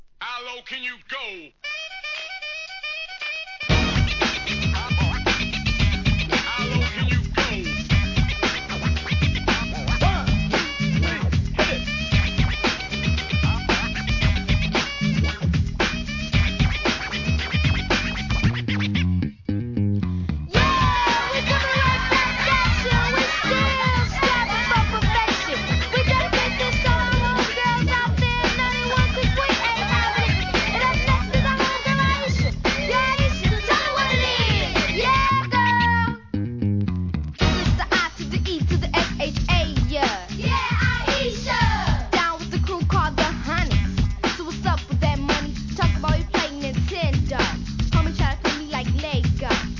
HIP HOP/R&B
大ネタのイケイケNEW JACK SWINGで少女RAPグループ!